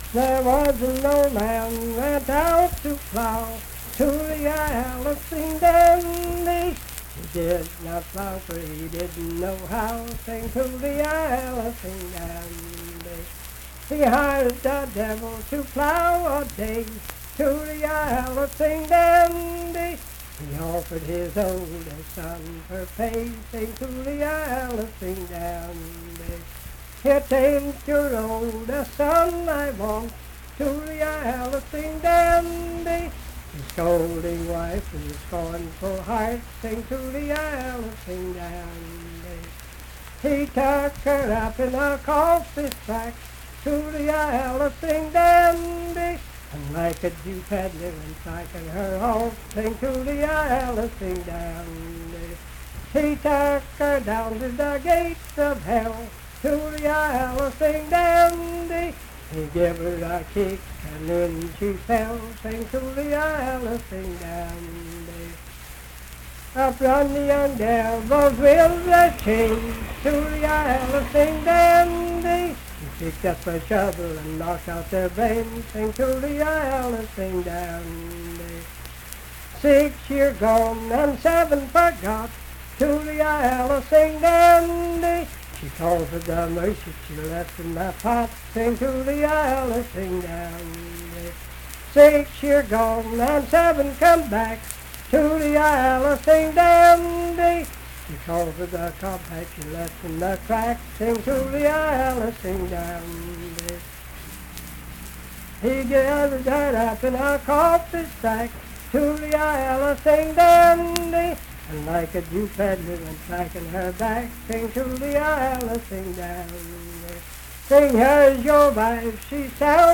Unaccompanied vocal music
Verse-refrain 10(4w/R).
Performed in Ivydale, Clay County, WV.
Voice (sung)